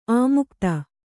♪ āmukta